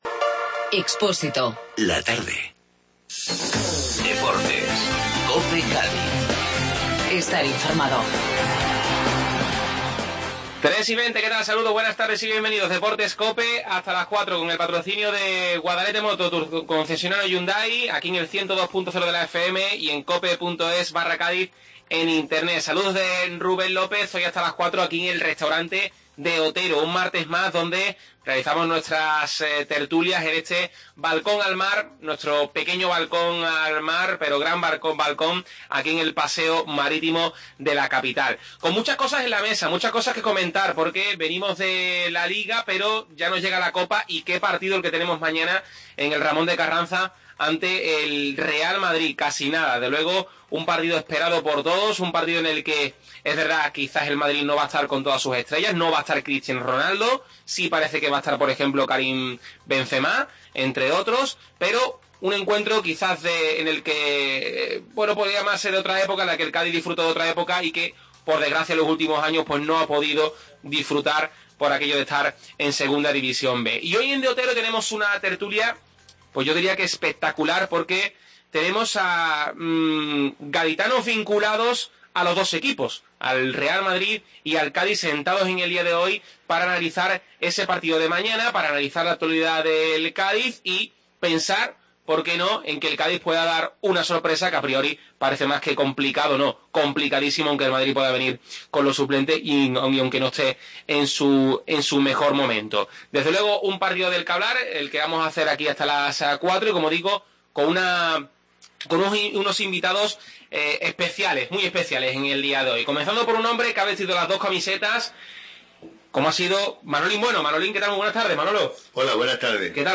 Desde el Restaurante De Otero previa del Cádiz vs Real Madrid